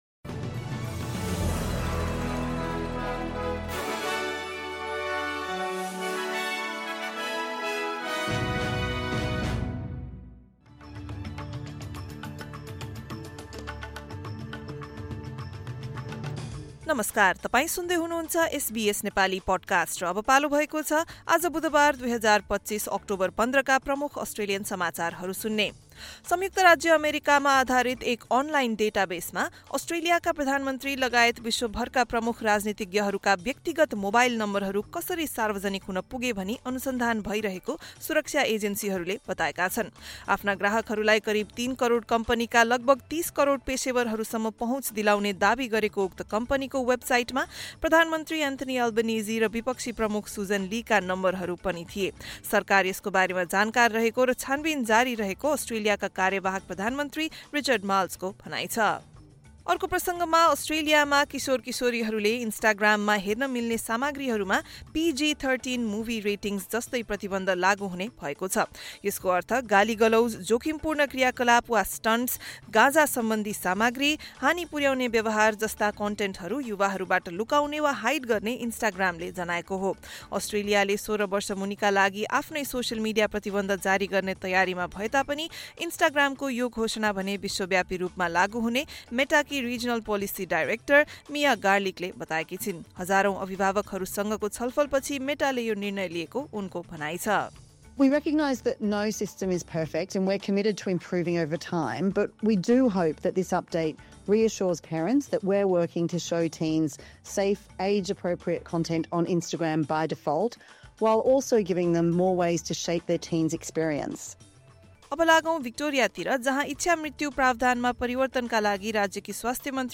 एसबीएस नेपाली प्रमुख अस्ट्रेलियन समाचार: बुधवार, १५ अक्टोबर २०२५